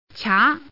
Index of /mahjong_paohuzi_Common_test1/update/1575/res/sfx/youxian/woman/